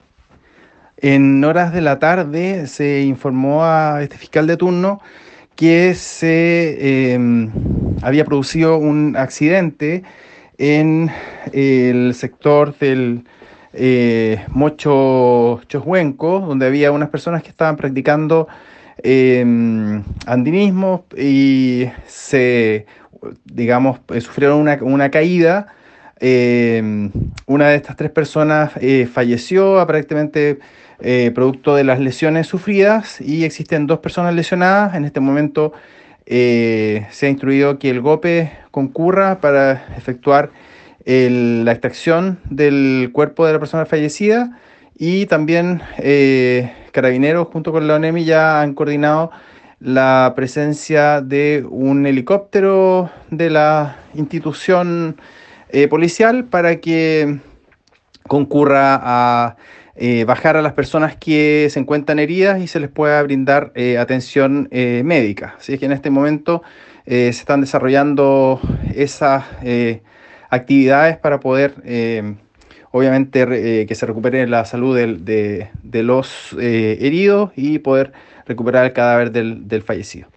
Fiscal Carlos Silva.